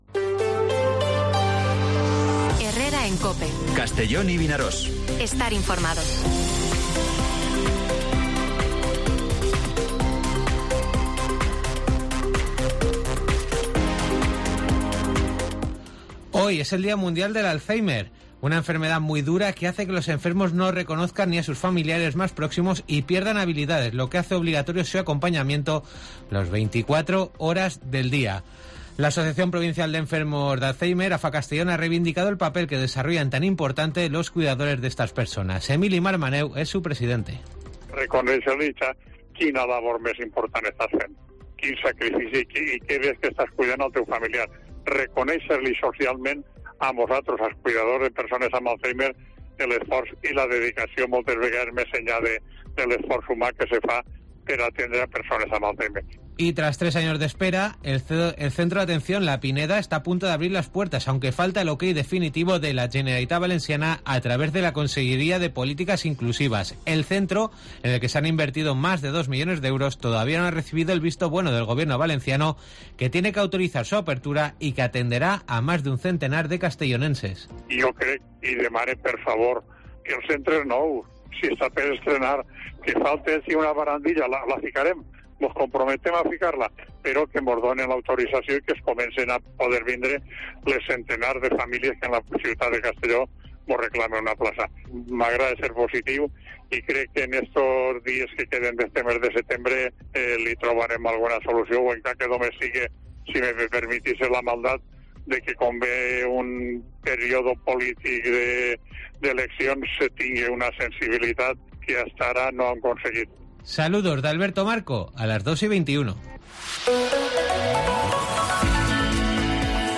Informativo Mediodía COPE en Castellón (21/09/2022)